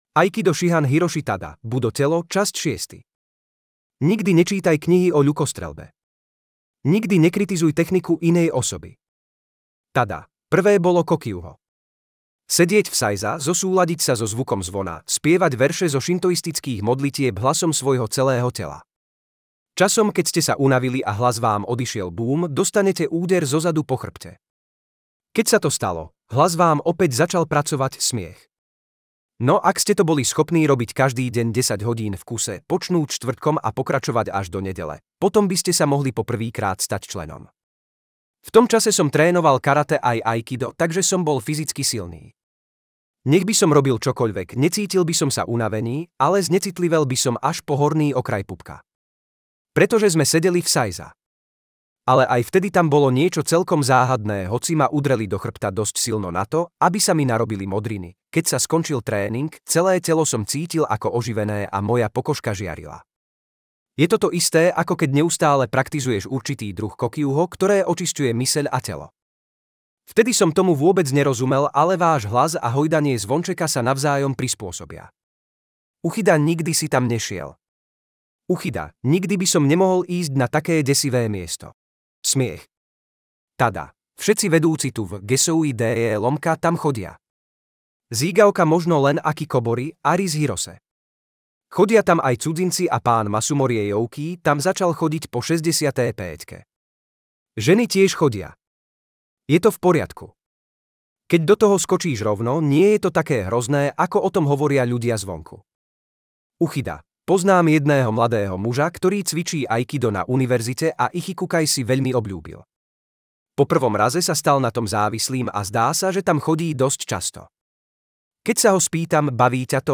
rozhovor - Tada Hiroshi - Budo telo - časť 6 - Aikido Dojo Trnava